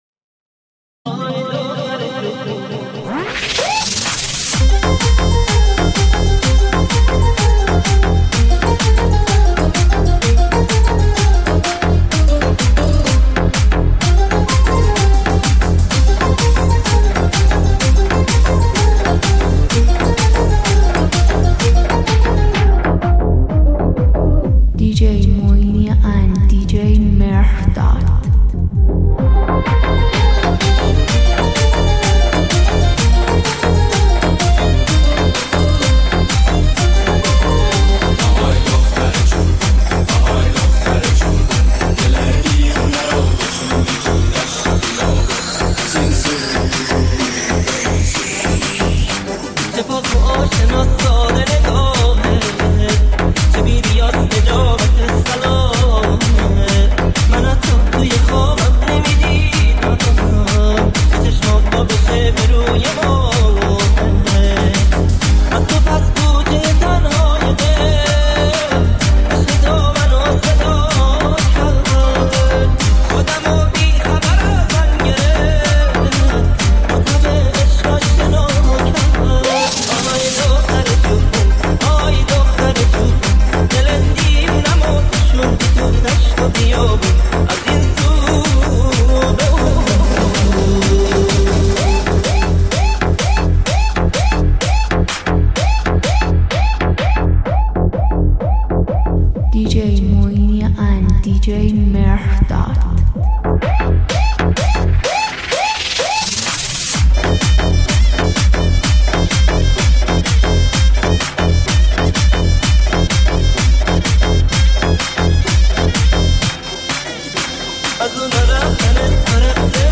آهنگ شاد